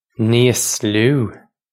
Pronunciation for how to say
neese loo
This is an approximate phonetic pronunciation of the phrase.